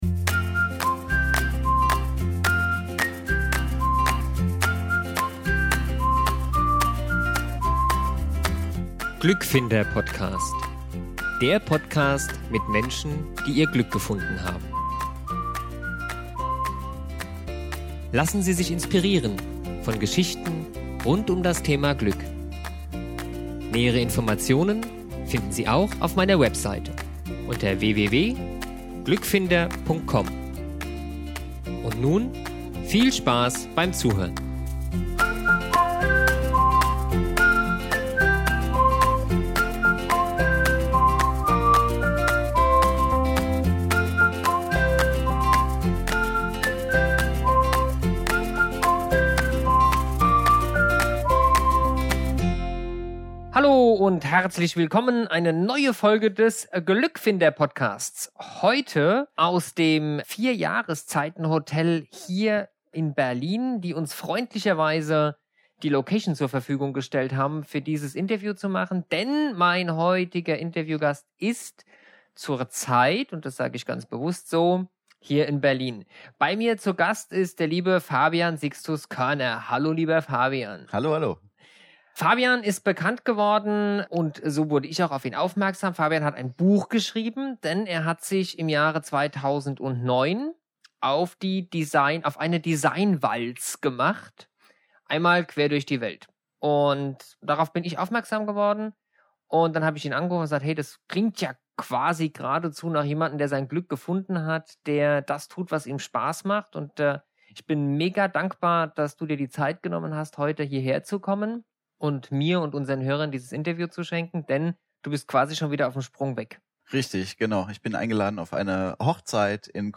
Das Hotel hat uns freundlicherweise ein extra großes Zimmer zur Verfügung gestellt, damit wir dieses Interview zum ersten Mal filmen konnten!